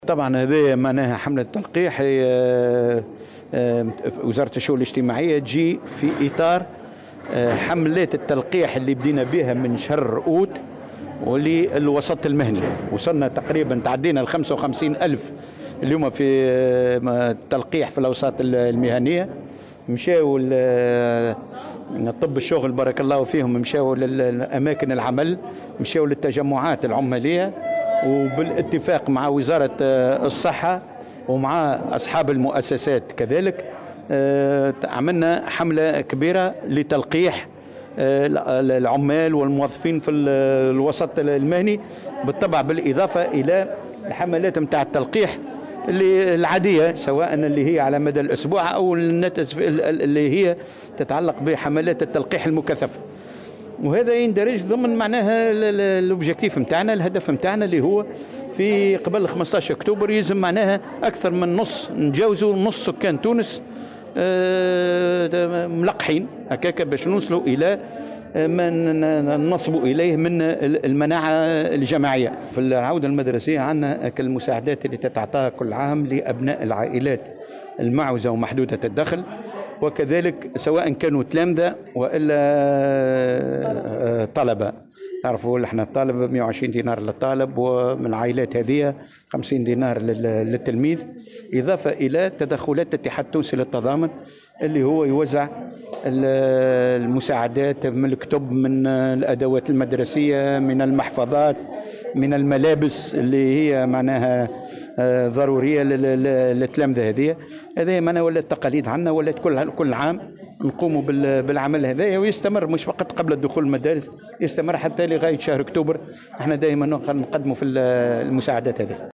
وأضاف في تصريح اليوم الثلاثاء، لمراسلة "الجوهرة أف أم"، على هامش إشرافه على حملة تلقيح لفائدة أعوان وزارة الشؤون الاجتماعية، أن حملات التلقيح في الأوساط المهنية انطلقت منذ شهر أوت، مثمنا الجهود التي قام بها أطباء الشغل، إضافة إلى حملات التطعيم العادية والمكثفة.